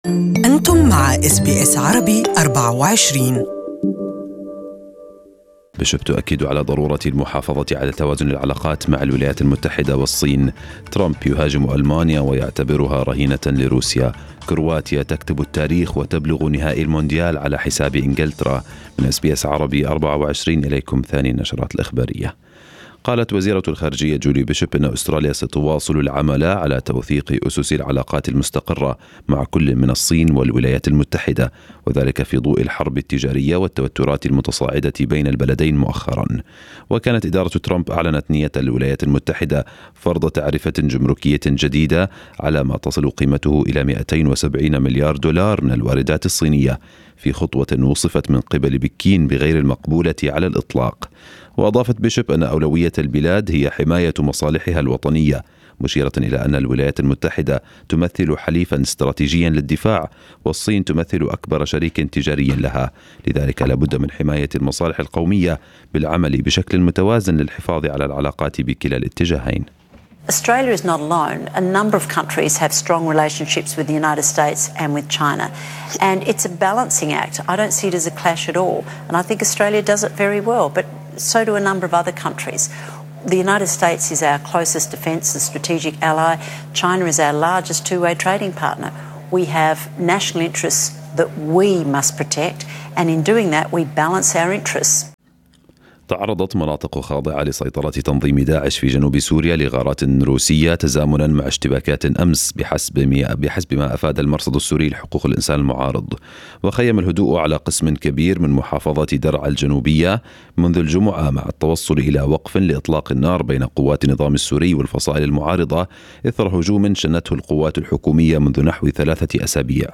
Arabic News Bulletin 12/07/2018